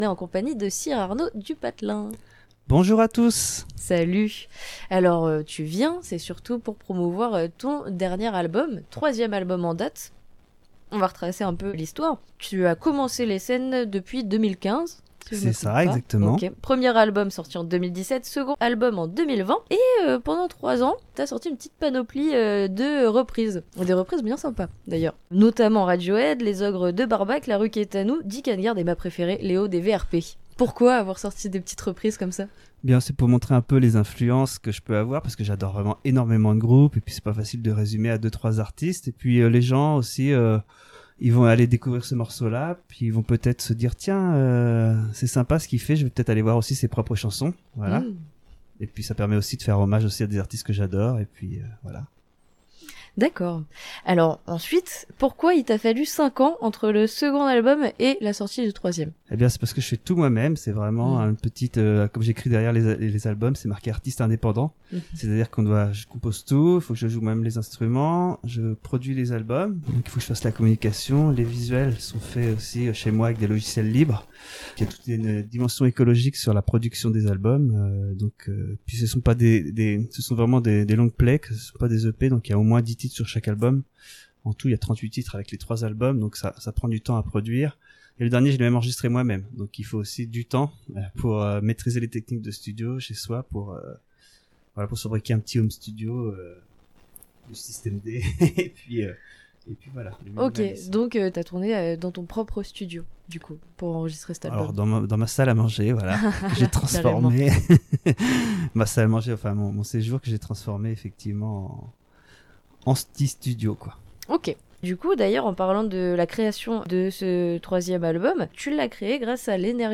Interviews RCDF Cyrarno du Patelin le Nouvel Album pour les 10 ans !!